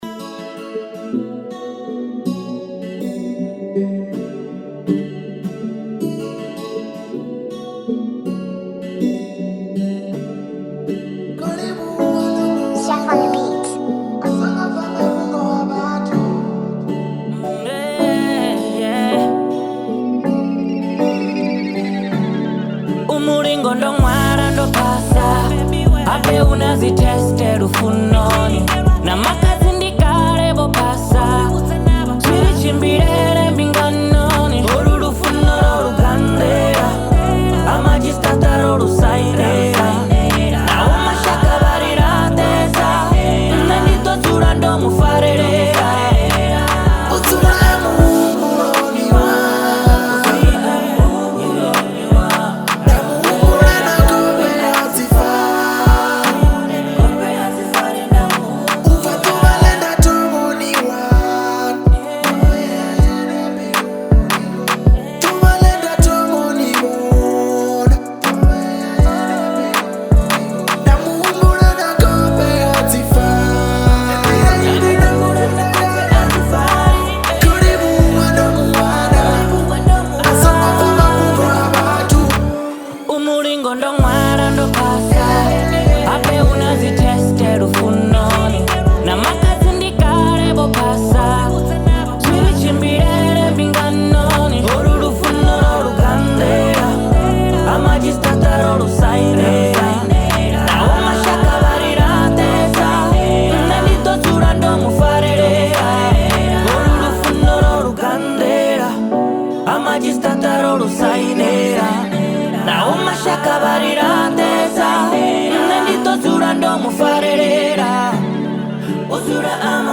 South African Gospel
Genre: Gospel/Christian.